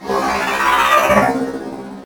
CosmicRageSounds / ogg / general / combat / enemy / droid / die2.ogg